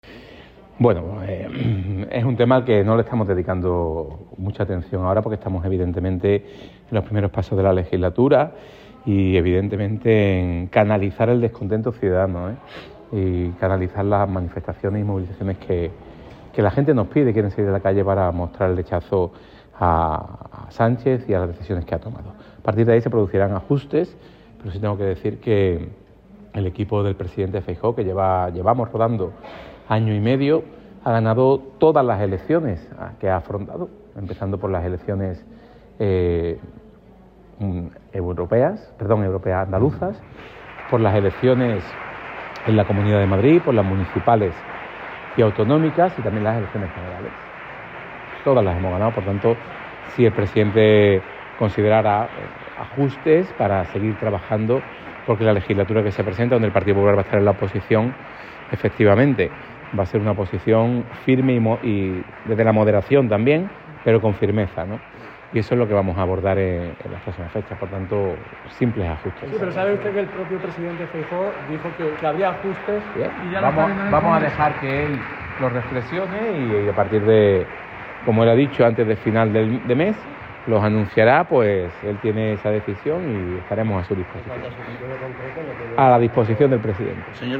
En declaraciones a los periodistas en Torremolinos, Bendodo ha señalado que «no le estamos dedicando mucha atención» a ese tema, ya que, ha dicho, «estamos en los primeros pasos de la legislatura y en canalizar el descontento ciudadano y las manifestaciones y movilizaciones que la gente nos pide y quieren salir a la calle para mostrar el rechazo a Pedro Sánchez y a las decisiones que ha tomado».